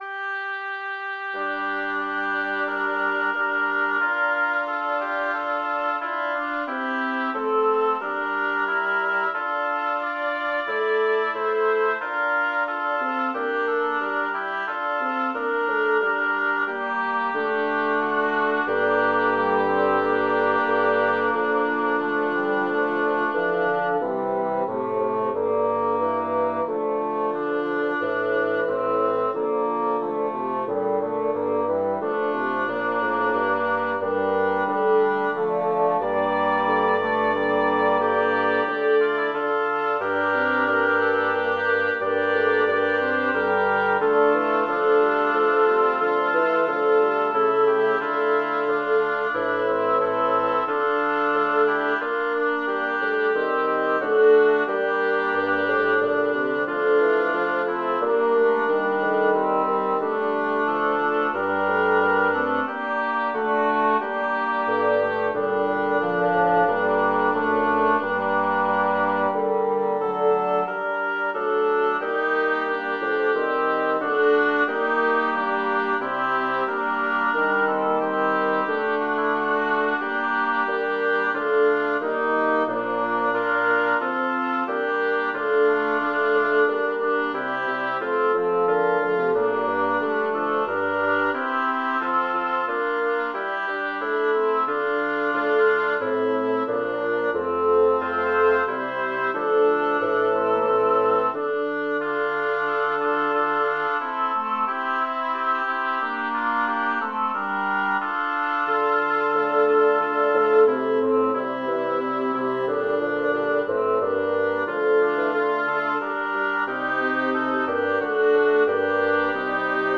Title: In te Domine speravi Composer: Claudio Merulo Lyricist: Number of voices: 6vv Voicings: STTBBB or SAATTB Genre: Sacred, Motet
Language: Latin Instruments: A cappella